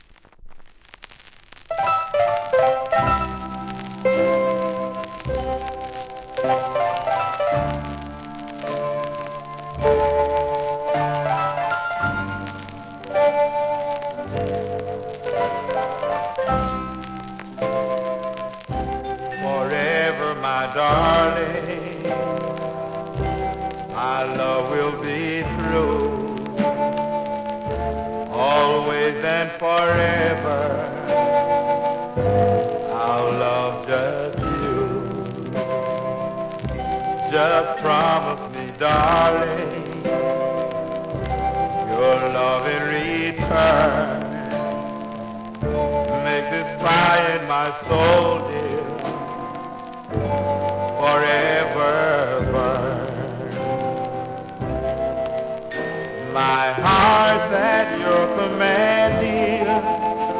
1. SOUL/FUNK/etc...